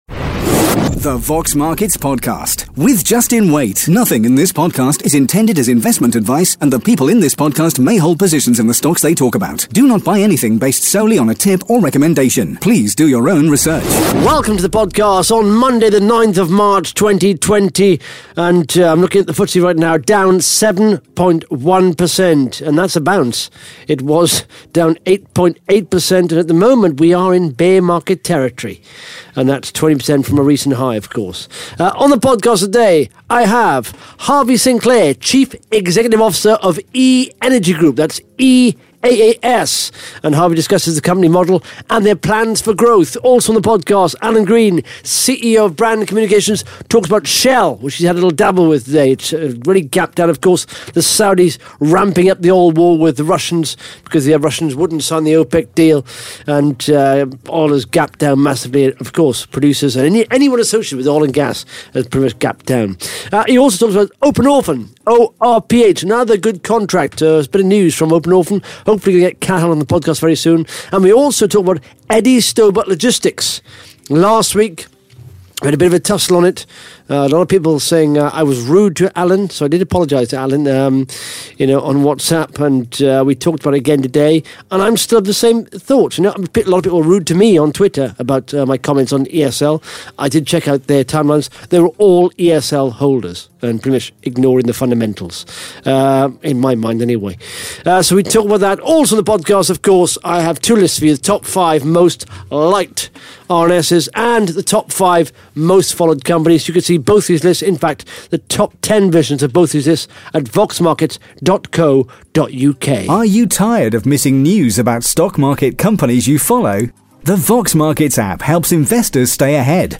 (Interview starts at 9 minutes 49 seconds)